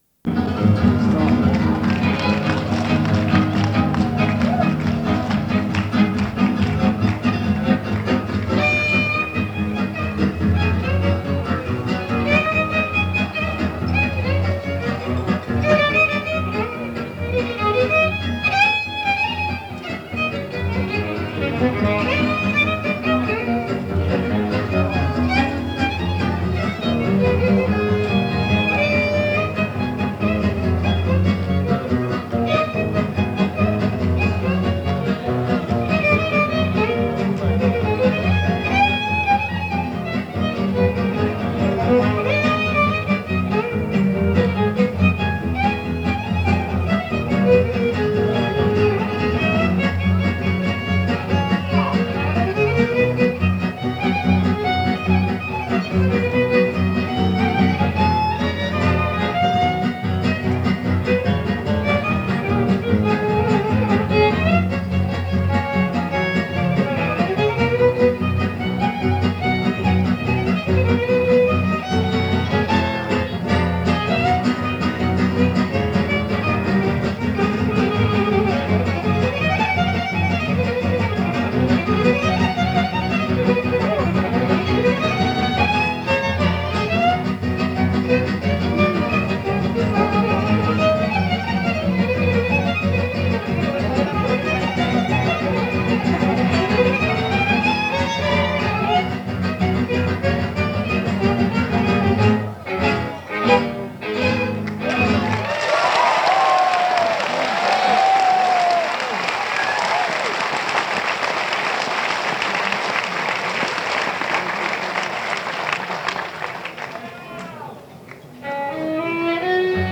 lead guitar
violin
rhythm guitar
bass